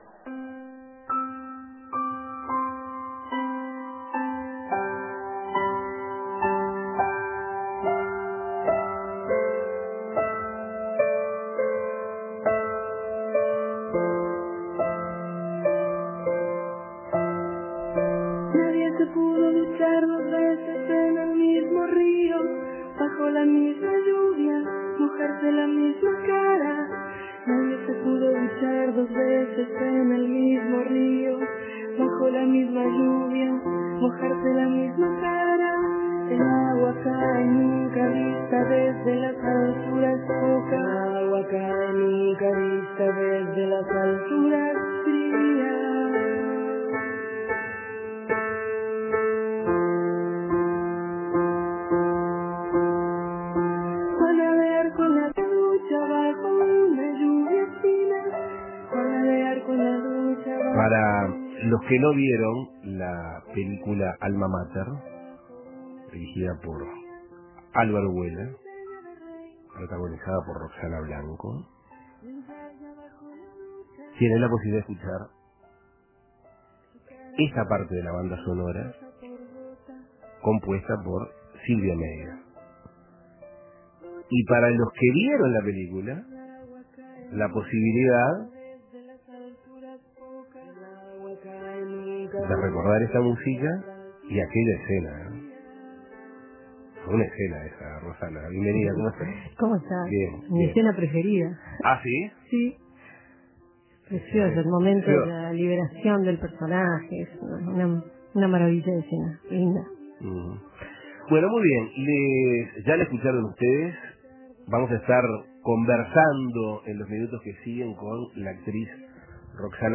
Asuntos Pendientes conversó con Roxana Blanco, actriz, protagonista de Alma Mater y multilaureada en los últimos tiempos, quien ahora trabaja para la productora argentina Pol-ka, que dirige Adrián Suar.